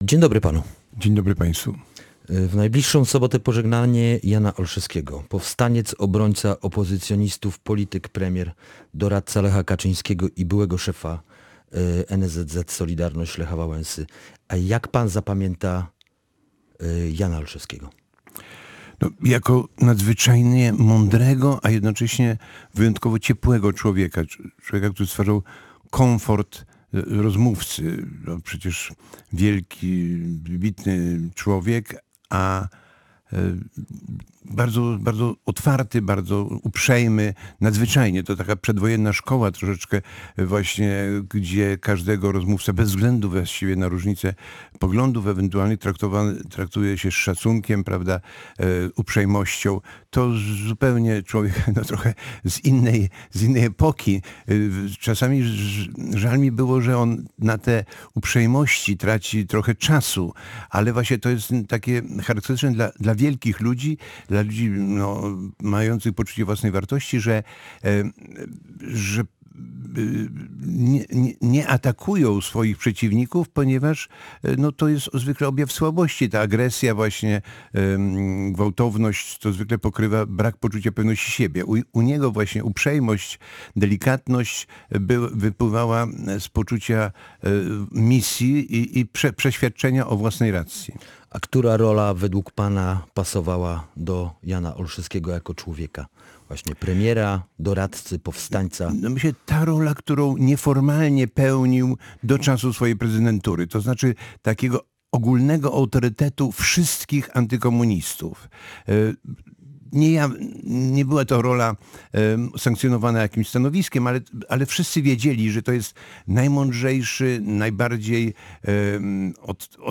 Jana Olszewskiego w Gościu Dnia Radia Gdańsk wspominał Krzysztof Wyszkowski, działacz Wolnych Związków Zawodowych.